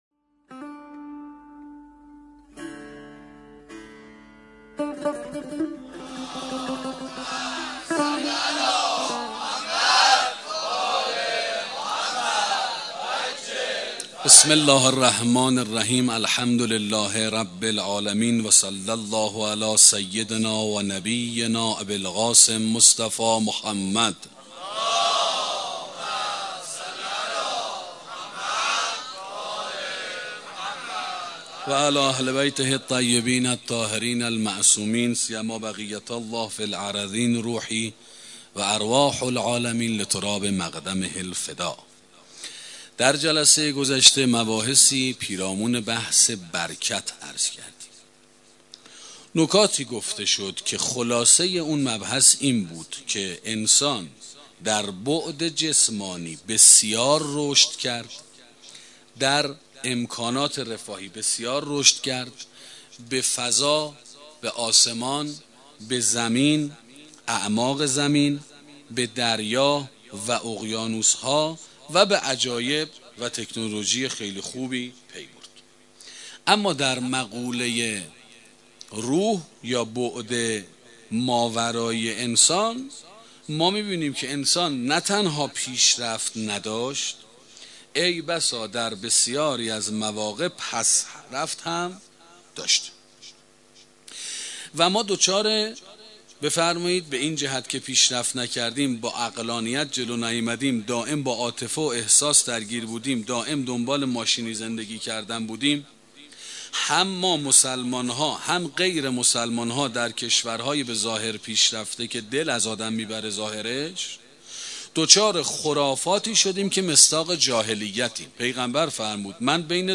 سخنرانی برکت 2